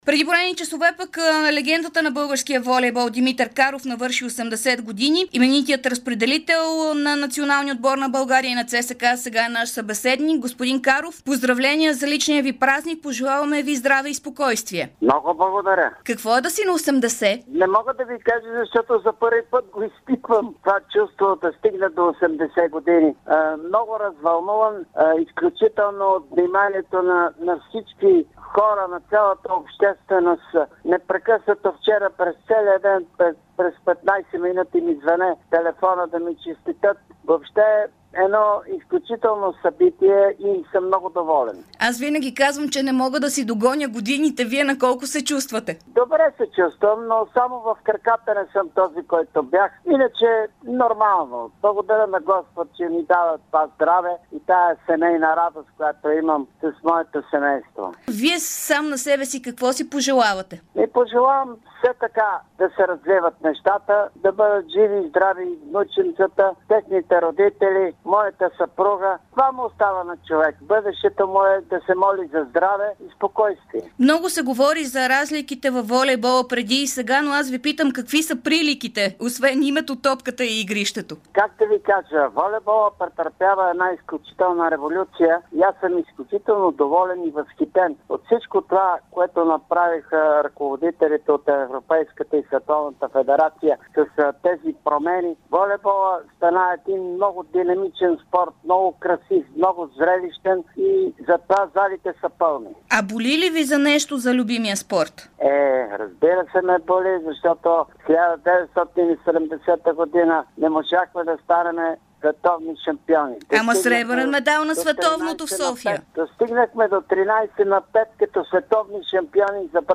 Легендата на българския волейбол Димитър Каров говори ексклузивно в ефира на Дарик радио часове, след като стана на 80 години. Дългогодишният национал, състезател на ЦСКА и най-добър разпределител в света в края на 60-те години коментира какво е чувството да бъде на 80, за разликите и развитието на волейбола в България, както и какво е най-важното за един човек.